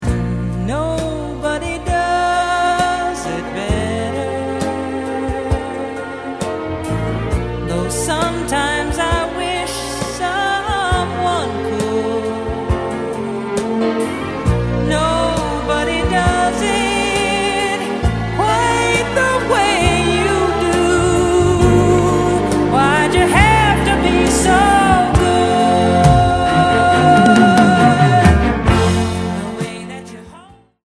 Tema principale del film